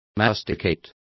Complete with pronunciation of the translation of masticate.